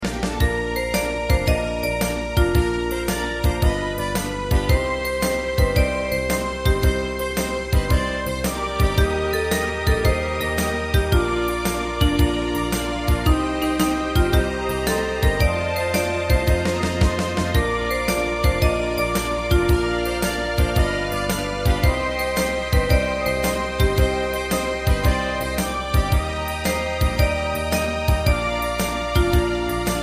大正琴の「楽譜、練習用の音」データのセットをダウンロードで『すぐに』お届け！
カテゴリー: ユニゾン（一斉奏） .
歌謡曲・演歌